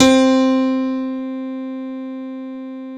Keys (3).wav